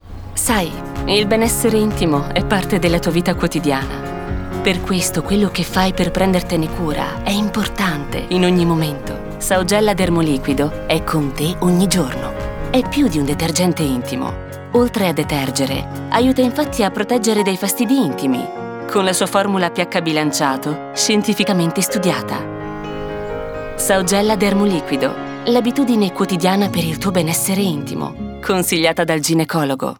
Female
Soft voice, warm, intense, suitable for institutional intonations, but also smiling and solar, dynamic and sparkling.
Television Spots